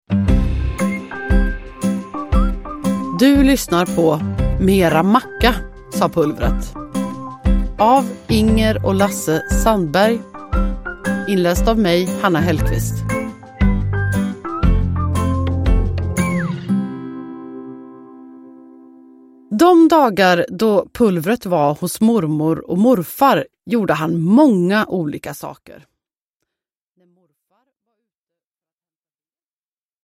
Uppläsare: Hanna Hellquist